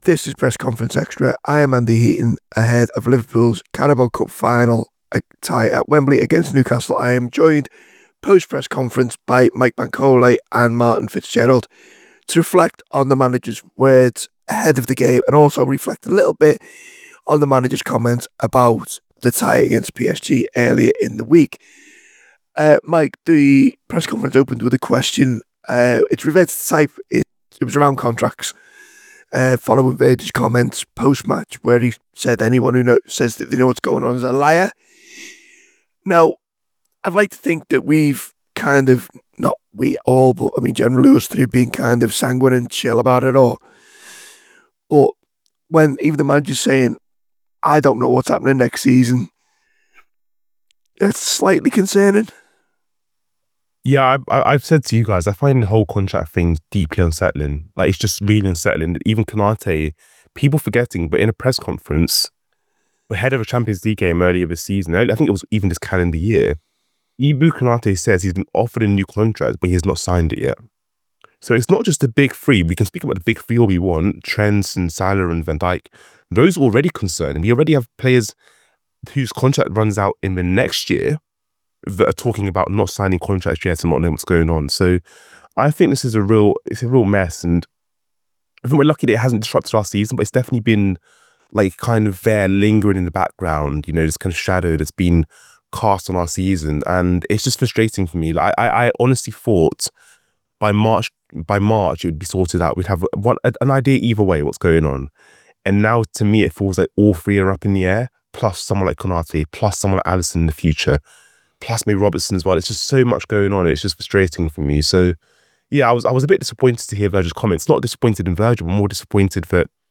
Below is a clip from the show – subscribe for more on the Liverpool v Newcastle United press conference…